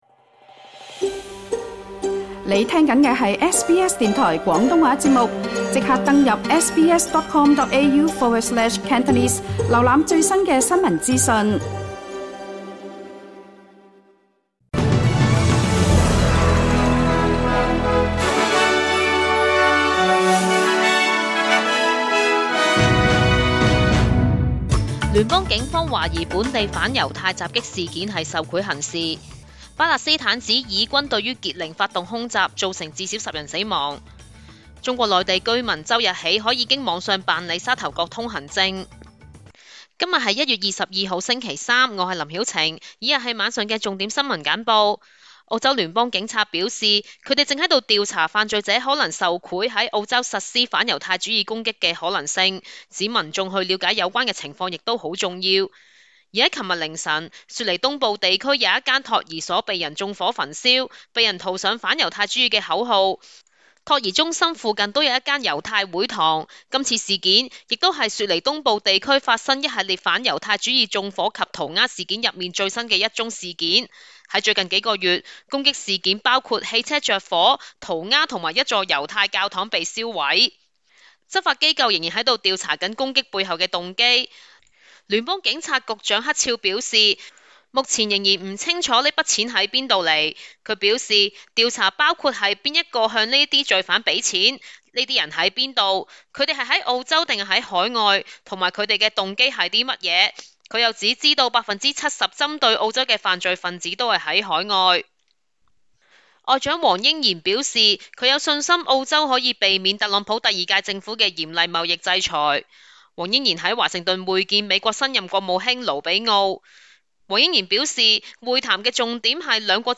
請收聽本台為大家準備的每日重點新聞簡報。